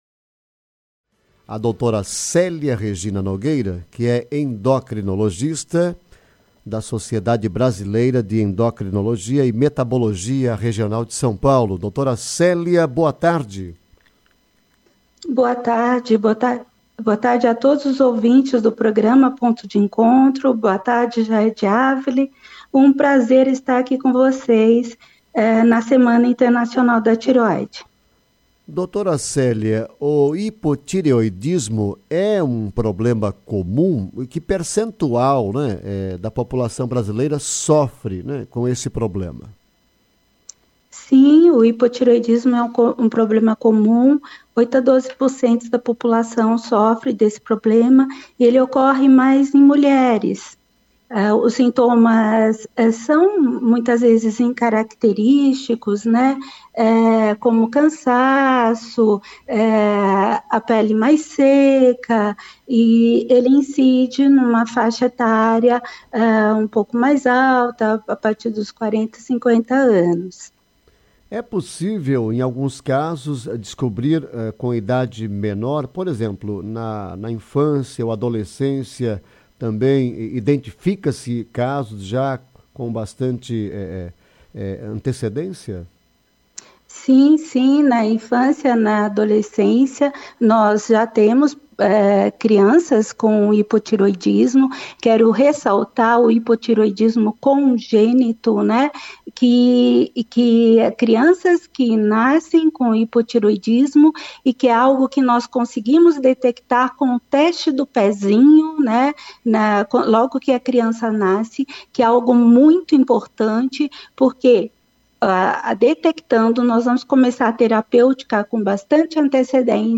Especialista explica sobre a tireoide e o hipotireoidismo em entrevista